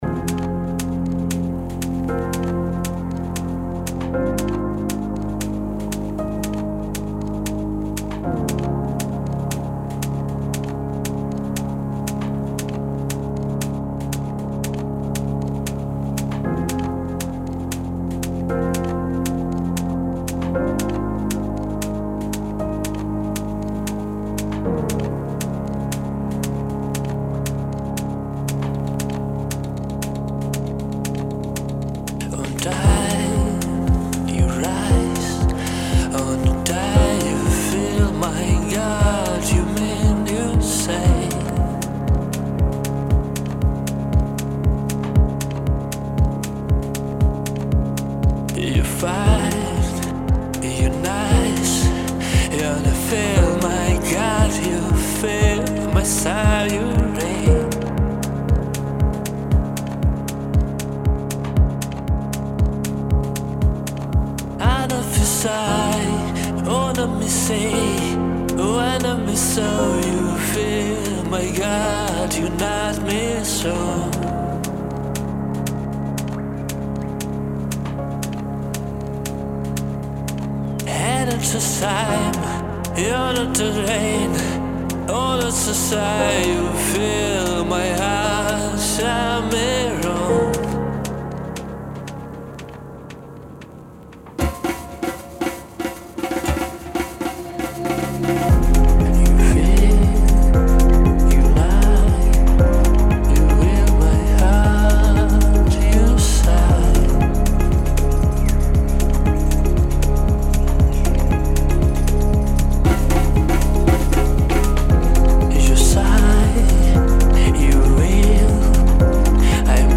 Single\Какой то House